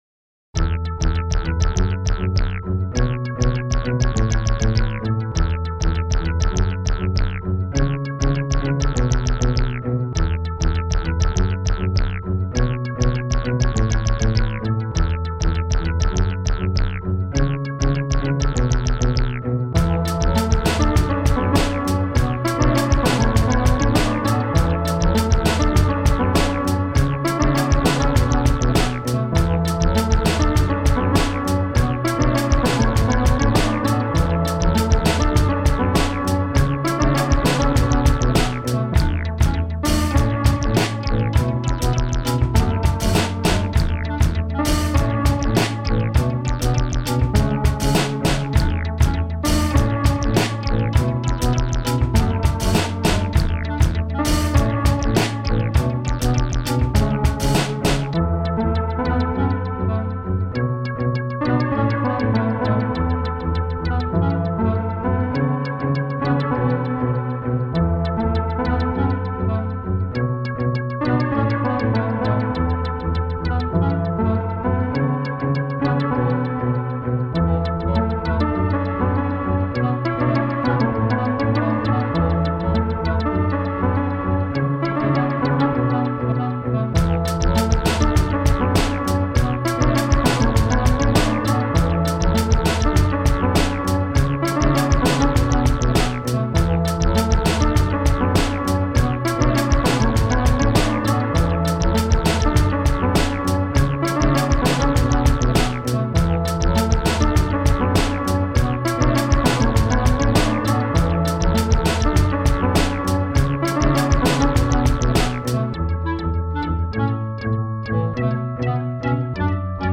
Музыка_детская_мультяшная.mp3